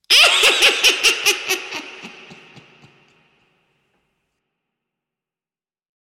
EvilClownLaugh.wav